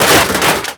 exp_metal_armor_detail02.wav